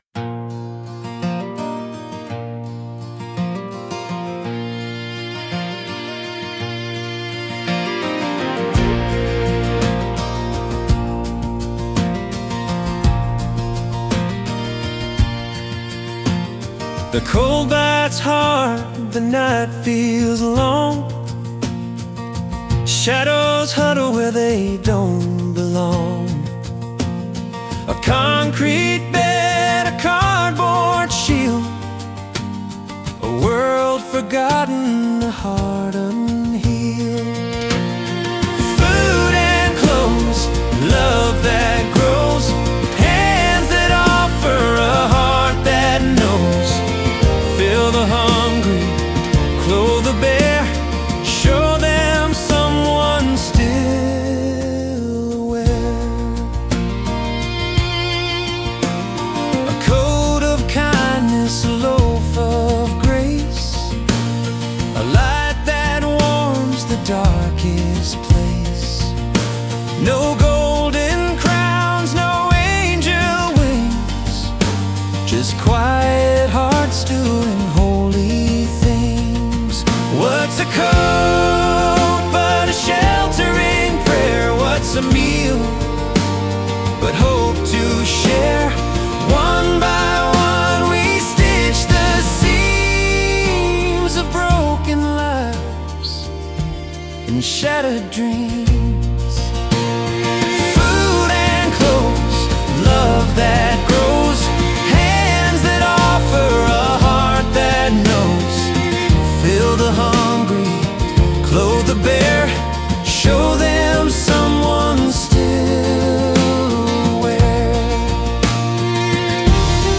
Love Steps In (Country, about caring for the homeless)